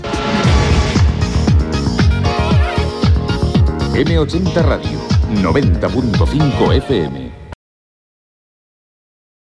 Jingle amb la freqüència d'emissió.